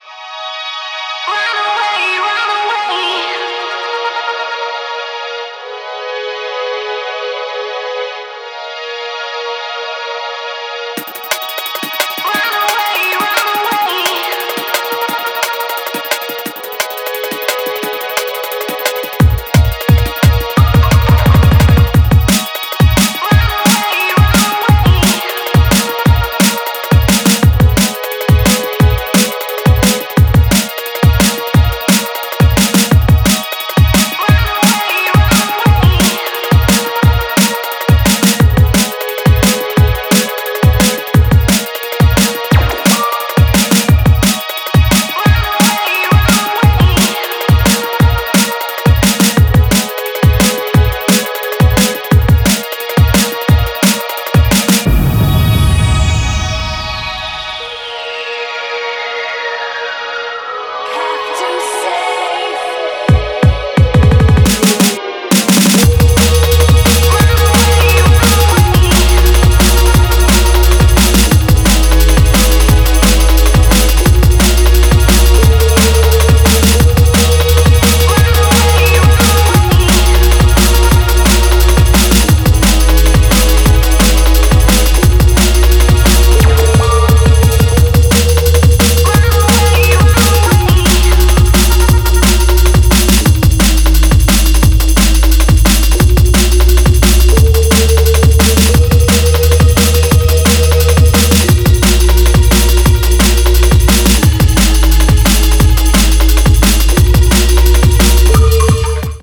Genres Drum & Bass